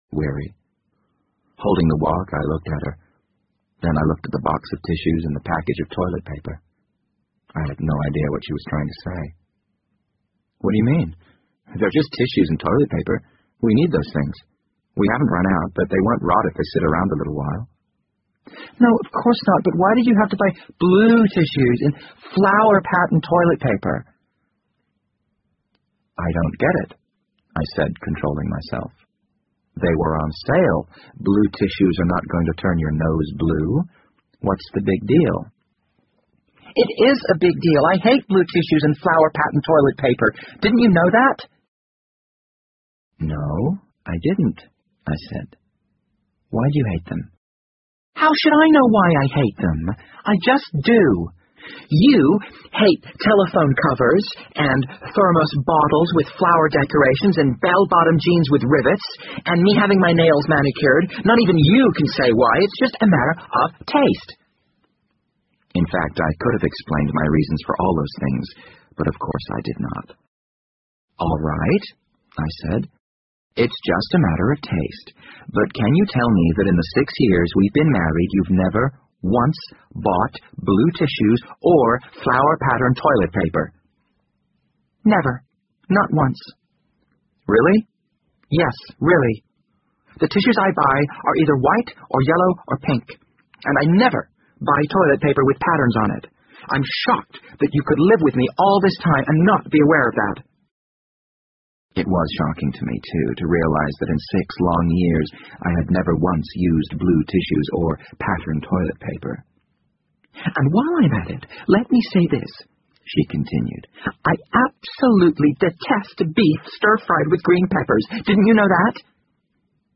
BBC英文广播剧在线听 The Wind Up Bird 12 听力文件下载—在线英语听力室